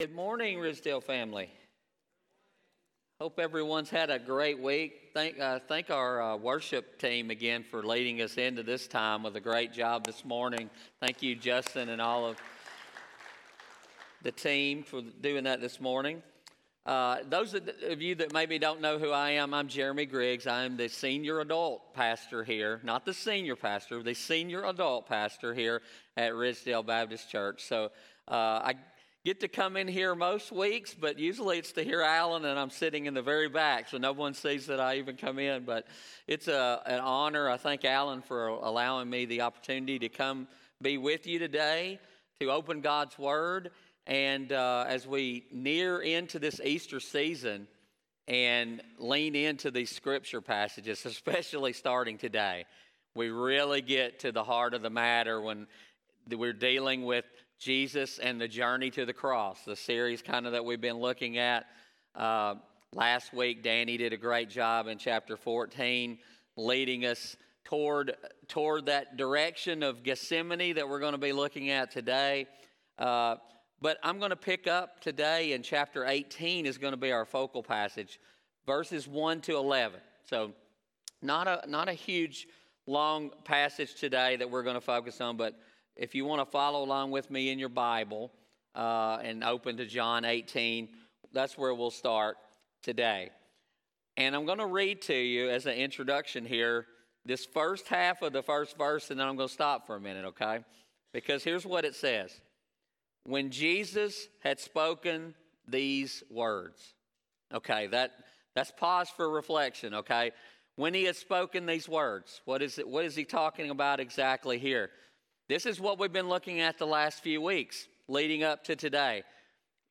Home › Resources › Sermons › John 18:1-11